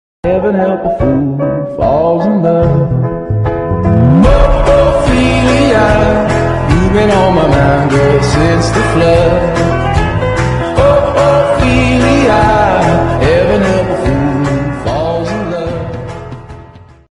happy vibes